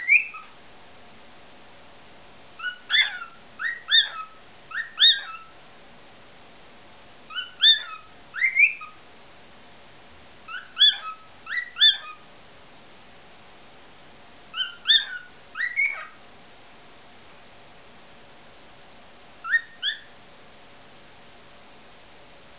Balzende Hähne
Nymphensittichhähne können sehr unterschiedliche Gesänge entwickeln.
singenderhahn4.wav